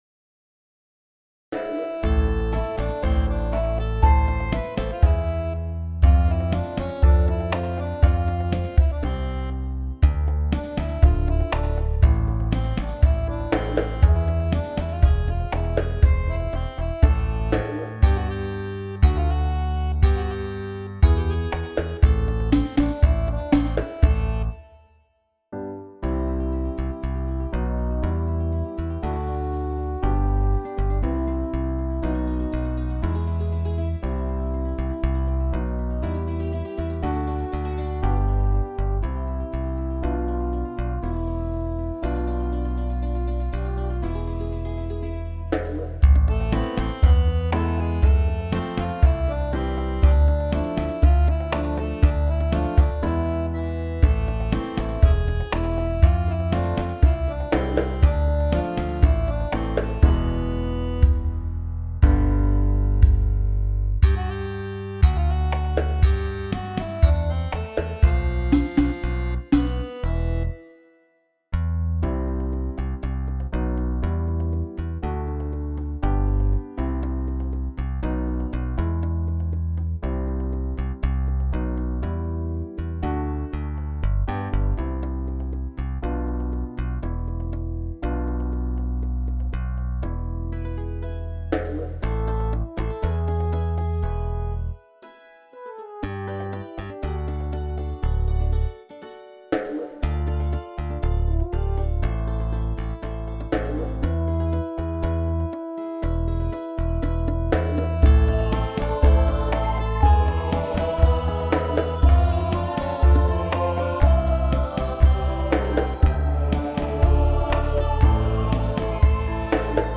Sound icon The little story in my mind here is that there were musicians from different countries who didn't speak the same languages, but they came together in the great hall while they were waiting and made some impromptu music.